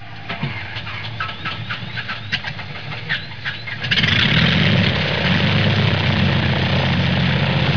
دانلود آهنگ طیاره 29 از افکت صوتی حمل و نقل
دانلود صدای طیاره 29 از ساعد نیوز با لینک مستقیم و کیفیت بالا
جلوه های صوتی